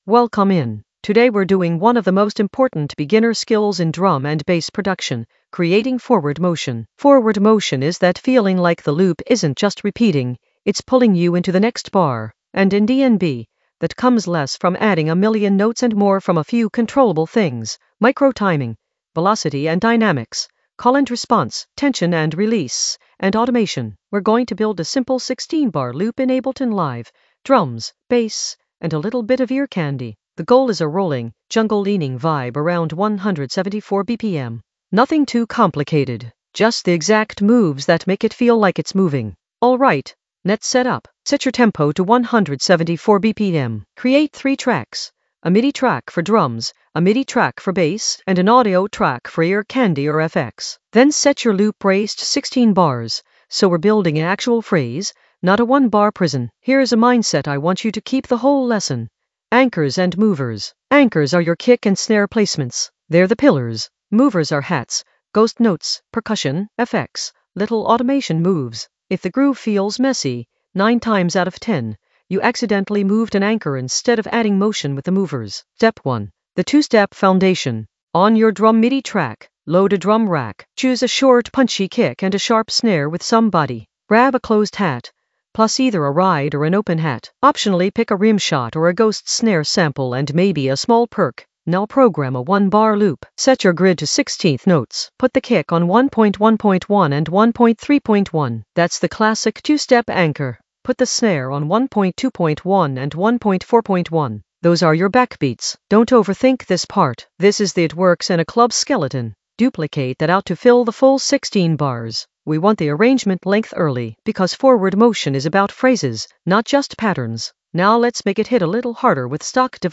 An AI-generated beginner Ableton lesson focused on Creating forward motion in the Groove area of drum and bass production.
Narrated lesson audio
The voice track includes the tutorial plus extra teacher commentary.